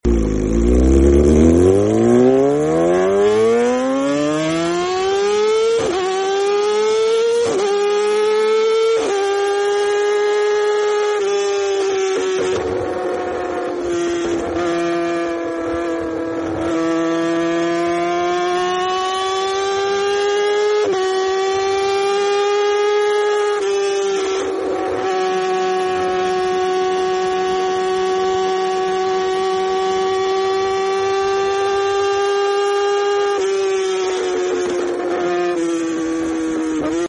Yamaha r6 pure sound on sound effects free download
Yamaha r6 pure sound on board,full exaust decat,